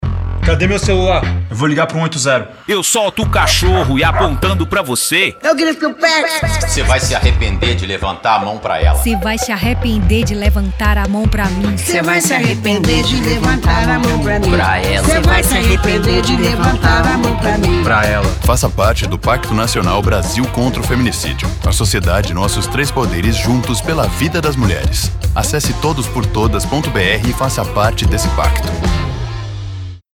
Dois vídeos, um de 30” e outro de 60”, e um spot de 30” sobre o tema foram produzidos pelo governo federal, com o apoio da ABERT, que poderão ser veiculados gratuitamente pelas emissoras de TV e rádio. O material chama a atenção para ações de prevenção, proteção, responsabilização de agressores e garantia de direitos das mulheres.